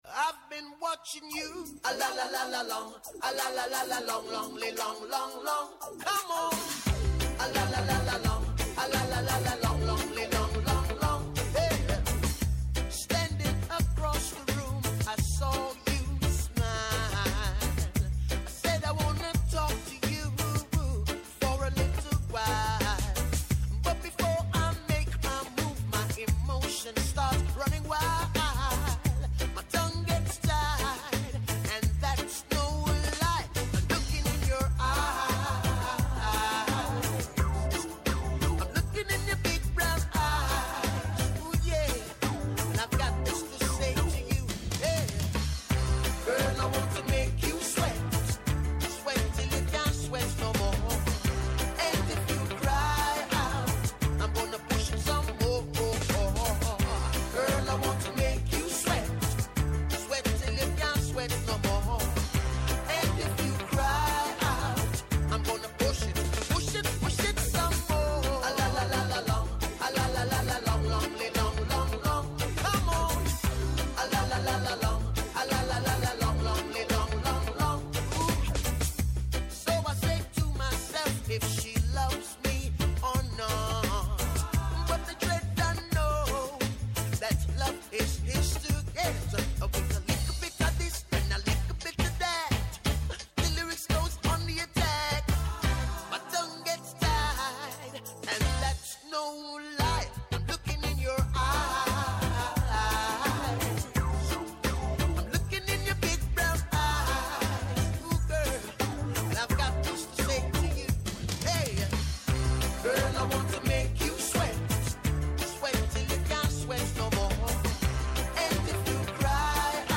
-ο Σωτήρης Αναγνωστόπουλος, Γενικός Γραμματέας Εμπορίου και Προστασίας Καταναλωτή